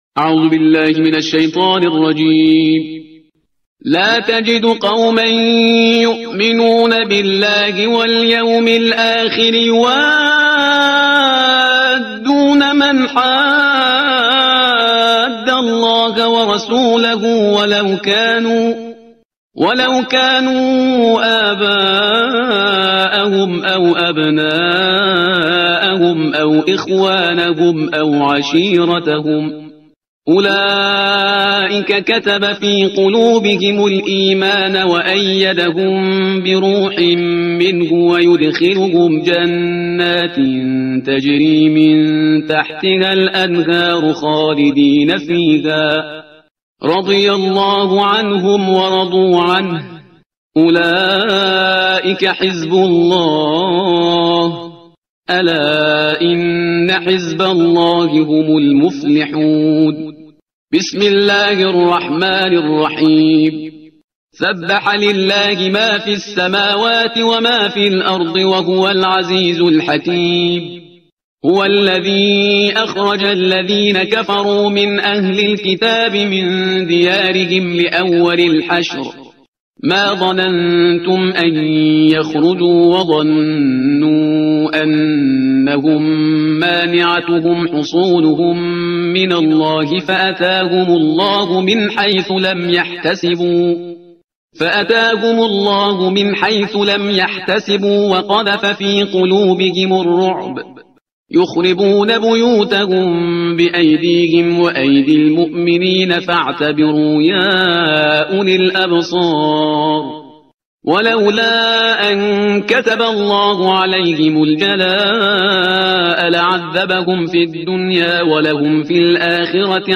ترتیل صفحه 545 قرآن – جزء بیست و هشتم